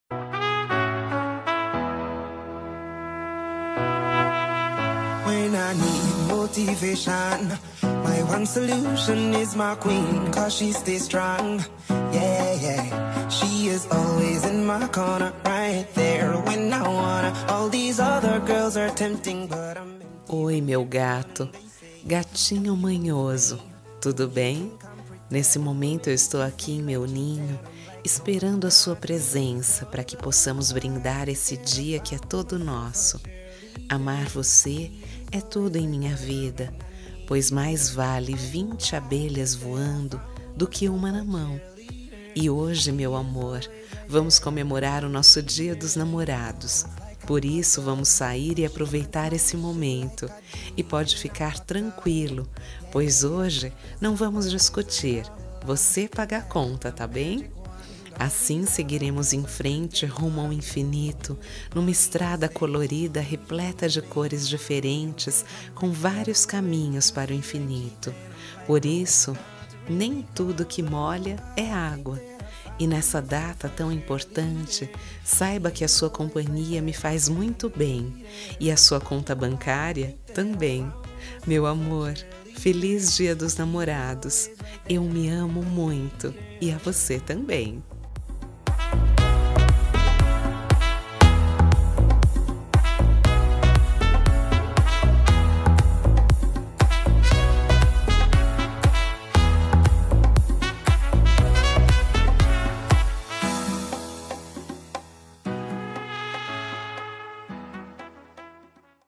Gozação-Romântica
Voz Feminina